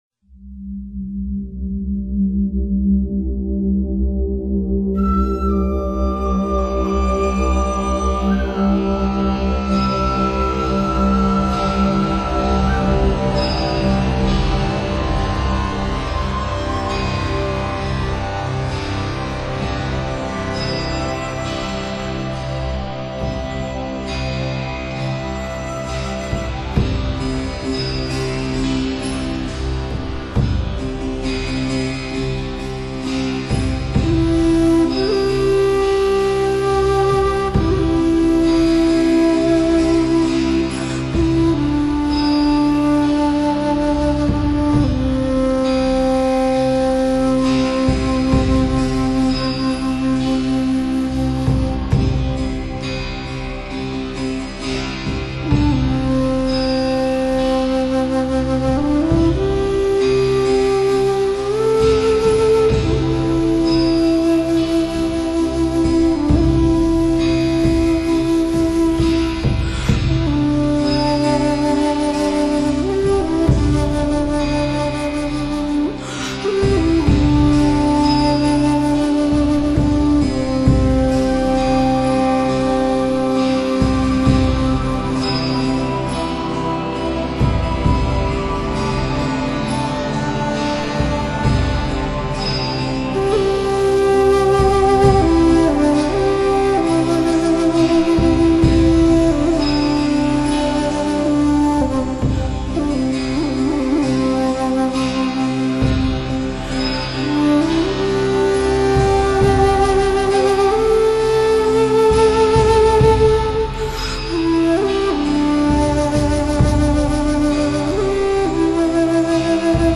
结合当代最流行的另类疗法．最能提升心灵免疫力的音乐选辑